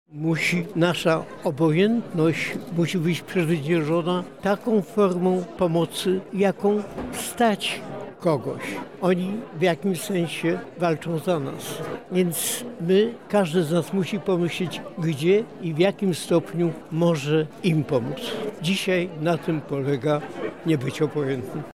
Ideą jaką kieruje się w życiu Marian Turski są słowa Nie bądź obojętny, zapytaliśmy go o to w kontekście dzisiejszych wydarzeń w Ukrainie.